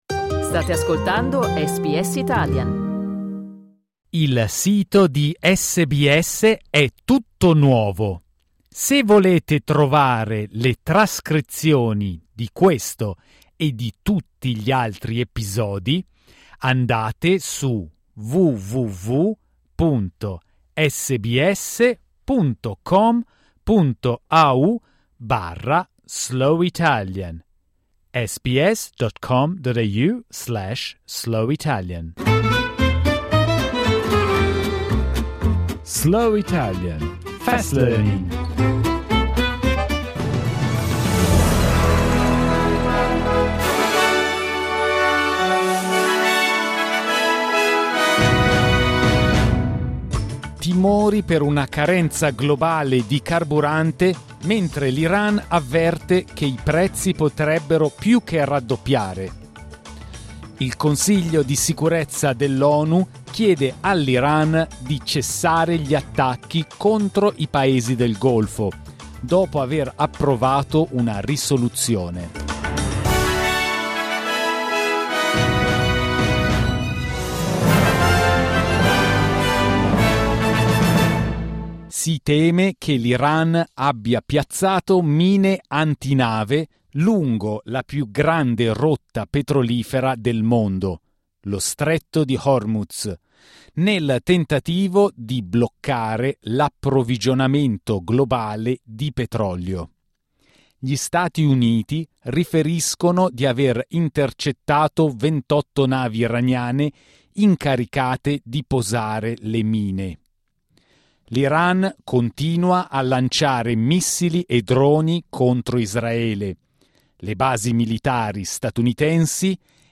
SBS Italian News bulletin, read slowly.